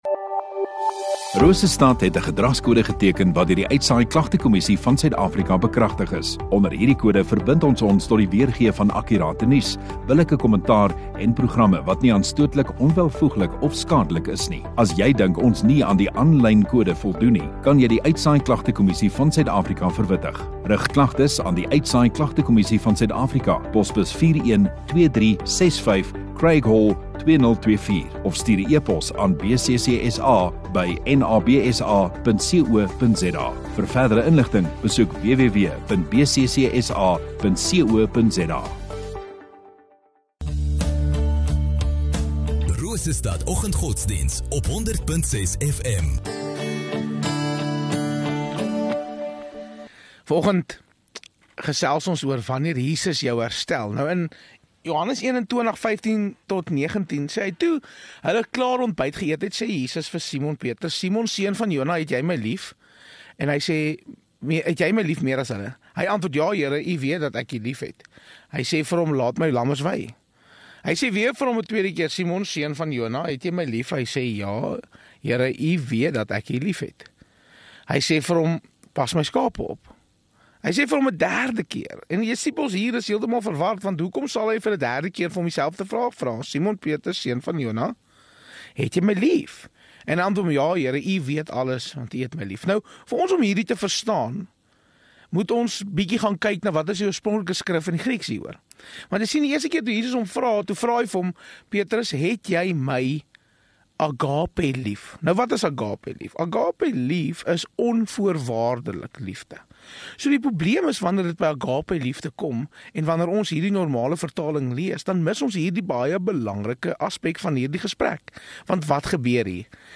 16 Apr Donderdag Oggenddiens